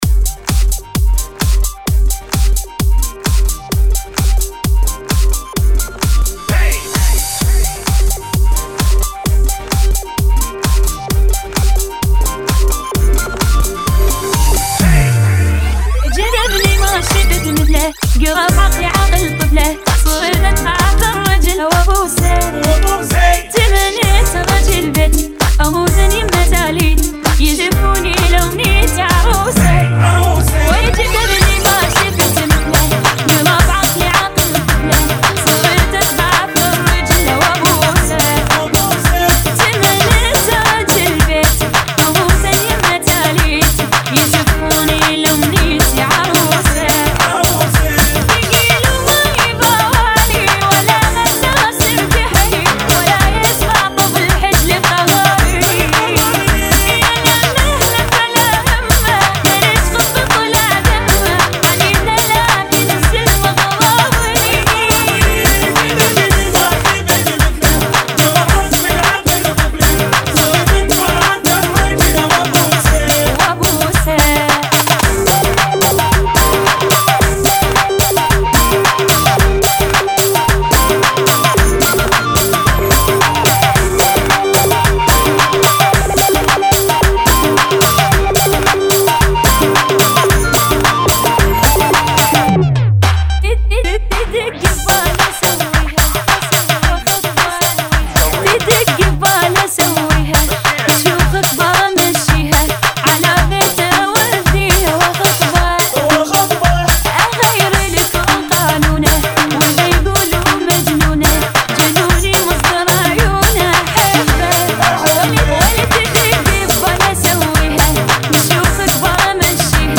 [ 130 Bpm - 110 Bpm ]